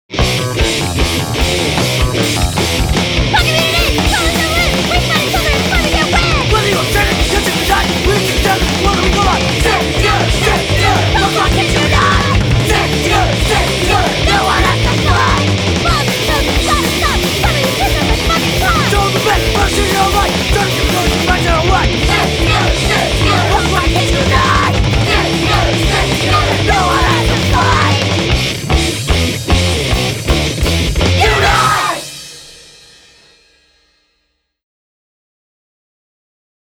punk rock See all items with this value